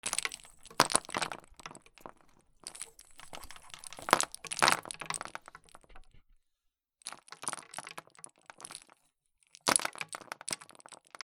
/ M｜他分類 / L01 ｜小道具 /
木のおもちゃ
『カラカラ』